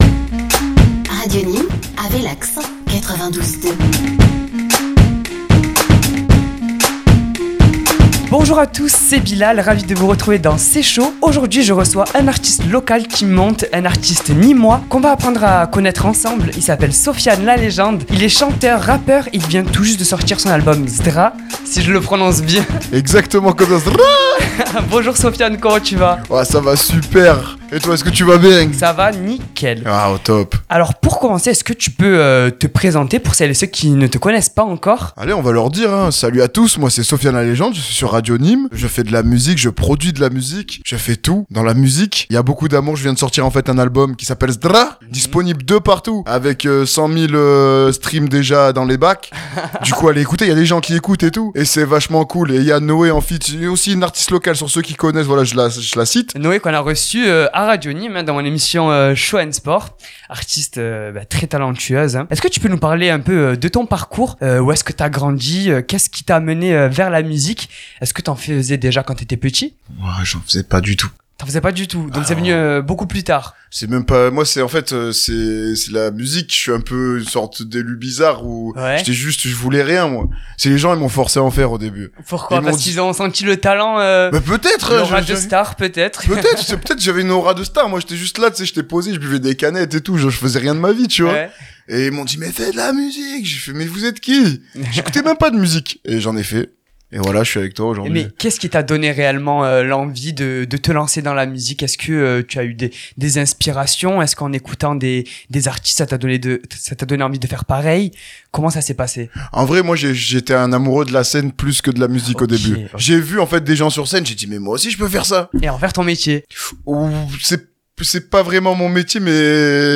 Il est venu parler de son parcours, de ses projets à venir, et de son dernier album Zdraaa. L’interview